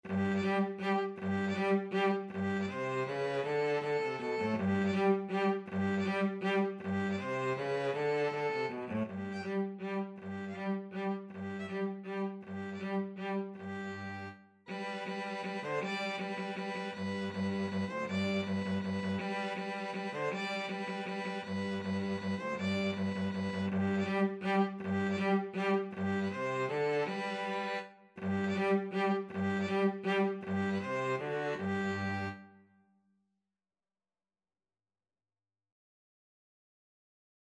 3/4 (View more 3/4 Music)
Moderato =160
Classical (View more Classical Violin-Cello Duet Music)